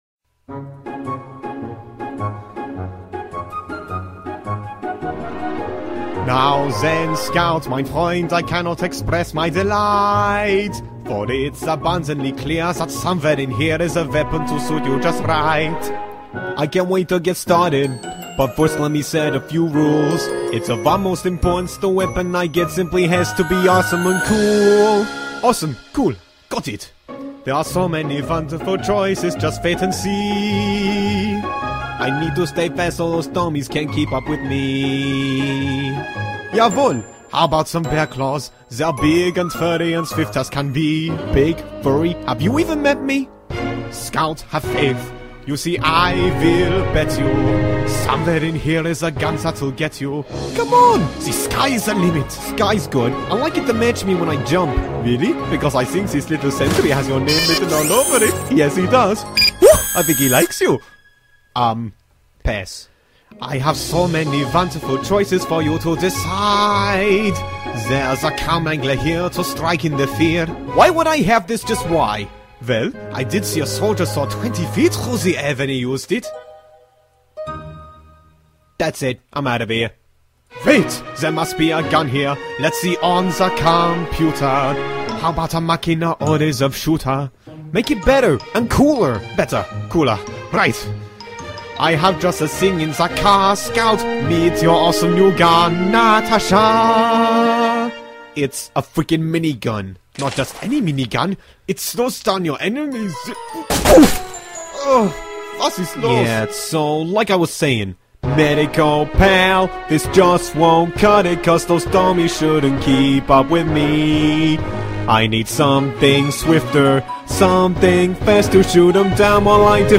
Featuring the Scout and Medic as our main vocalists.